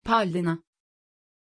Pronunciation of Paulina
pronunciation-paulina-tr.mp3